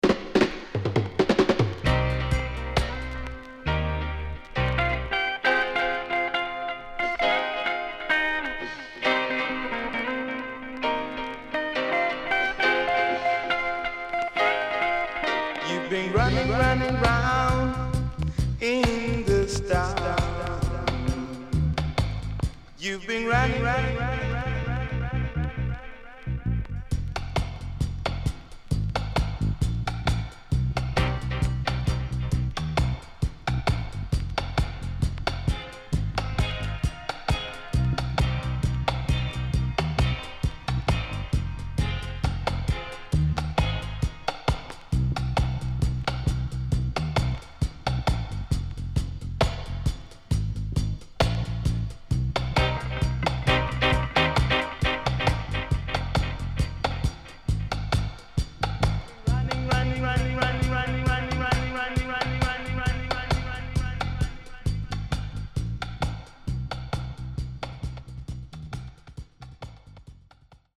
HOME > REGGAE / ROOTS  >  KILLER & DEEP  >  STEPPER
SIDE A:所々チリノイズがあり、少しプチノイズ入ります。